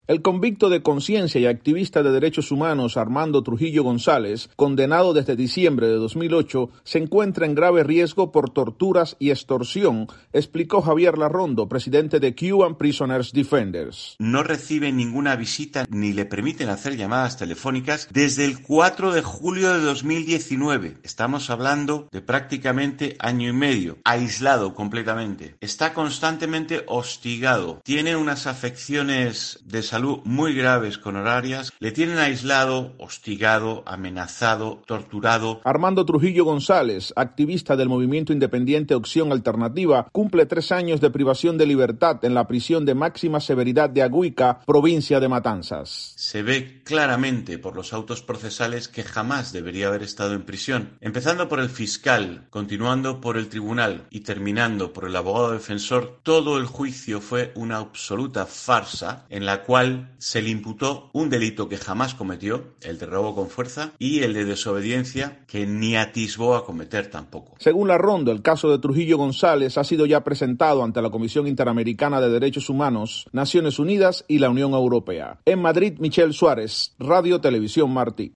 reportaje radial